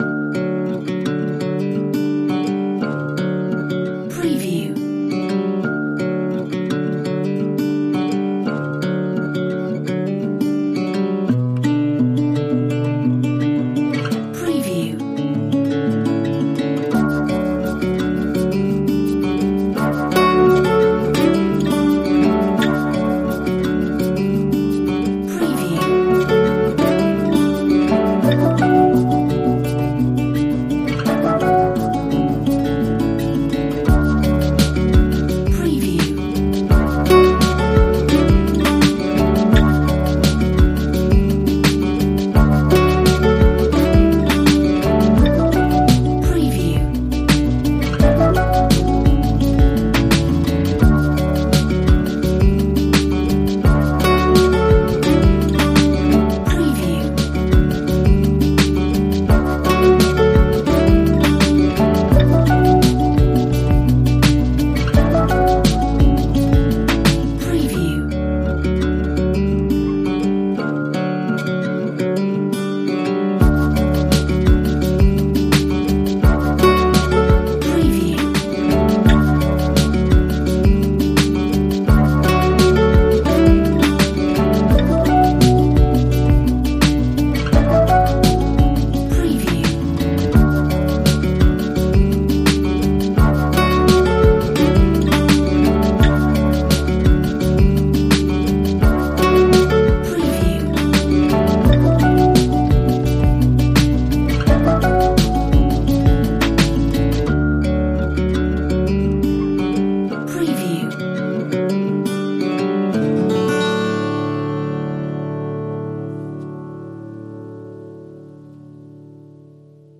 Earthy acoustic music track